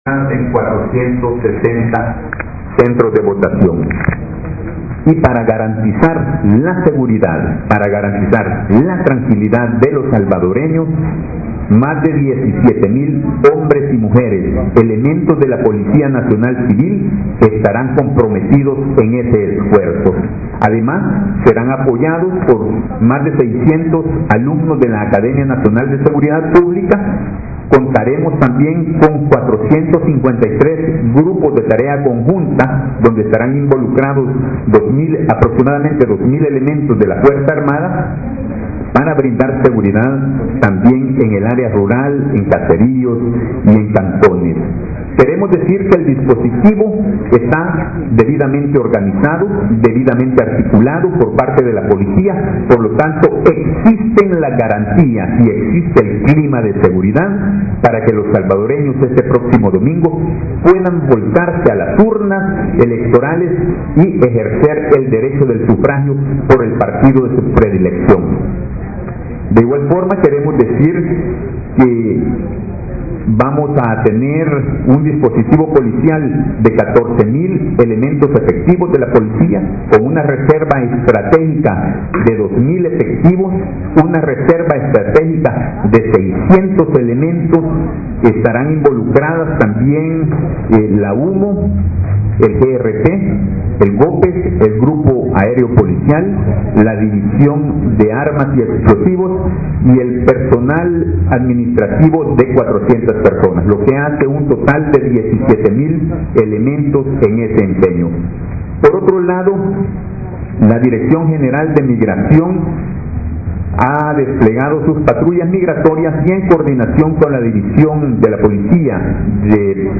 Declaraciones de René Figueroa y José Luis Tobar Prieto ministro de seguridad y director de la PNC